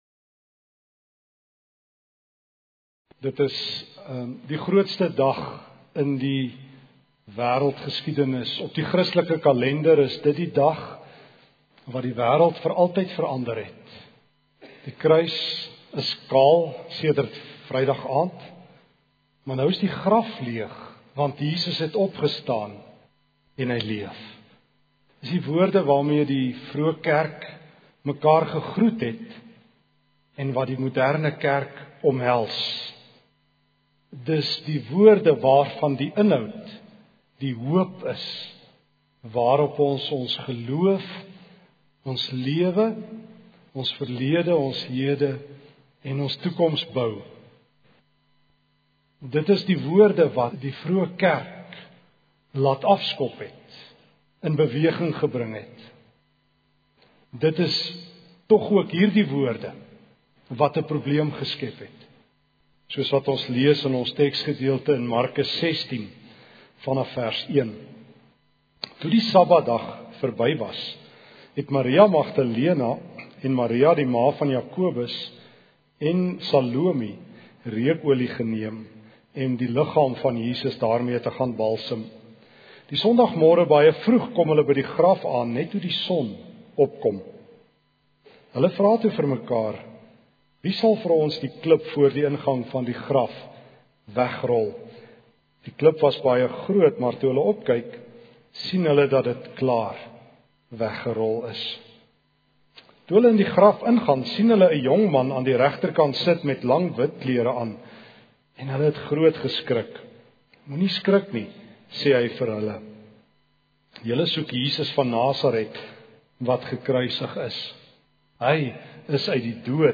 Prediker